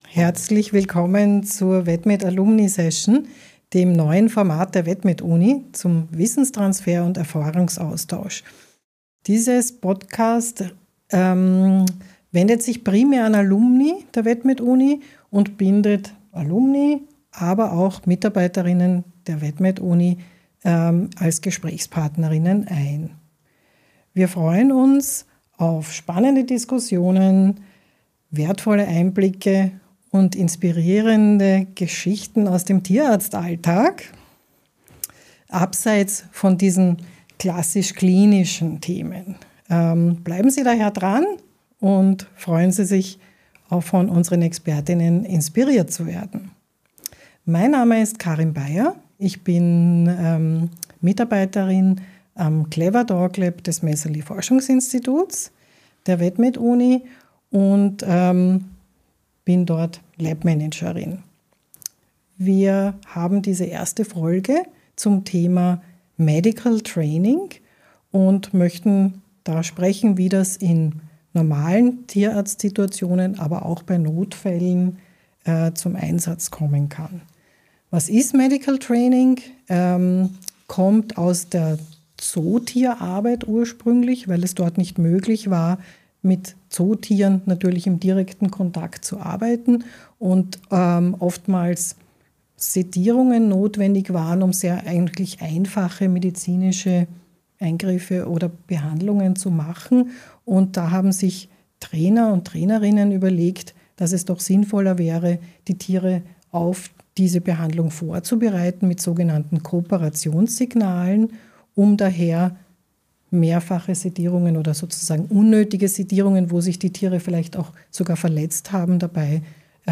Expert:innen aus Praxis und Forschung erörtern Medical Training aus unterschiedlichen Blickwickeln und tauschen sich über ihre Erfahrungen und wissenschaftliche Erkenntnisse aus.